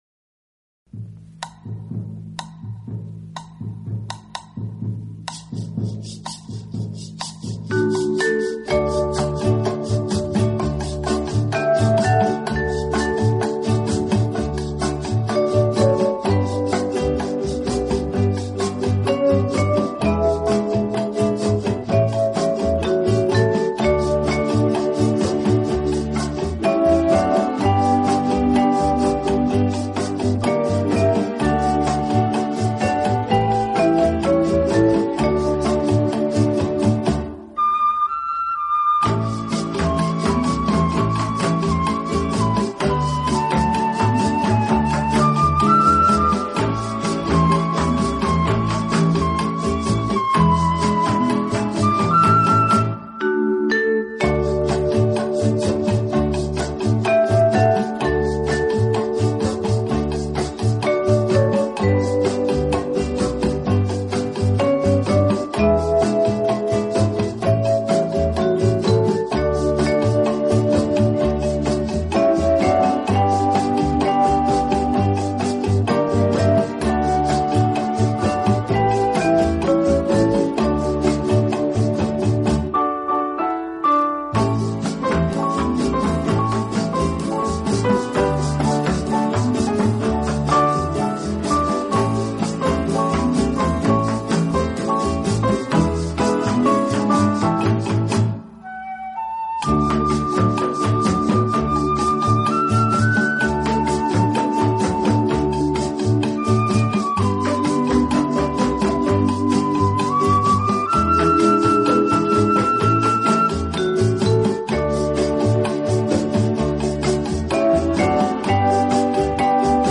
【轻音乐专辑】
是一位录音艺术家，他录制了大量的轻音乐，器乐曲的专辑，这些专辑在整个60年代